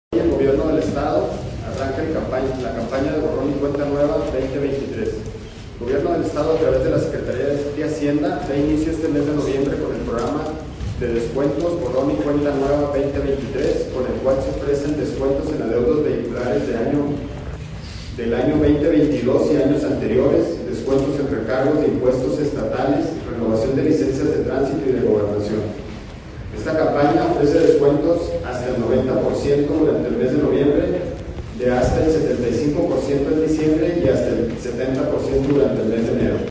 AUDIO: JOSÉ DE JESÚS GRANILLO, SECRETARÍA DE HACIENDA DEL ESTADO